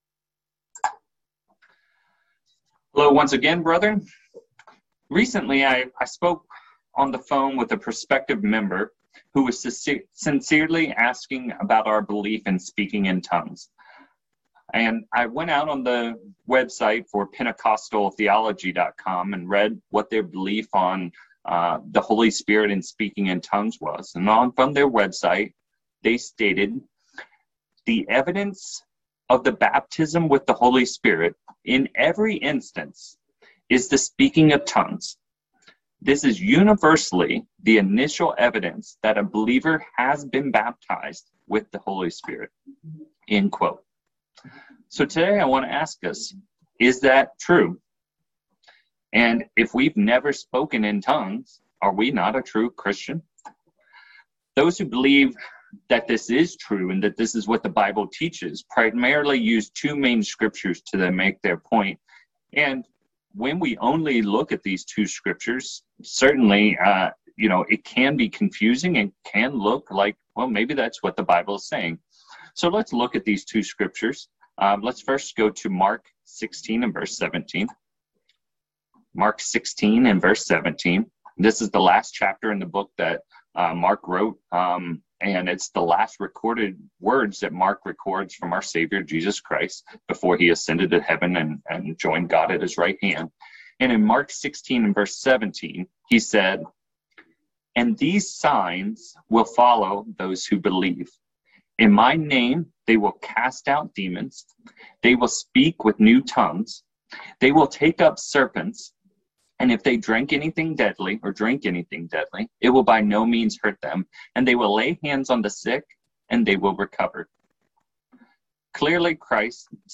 In this sermon, we’ll look at what the Bible truly teaches about the gift of Speaking in Tongues.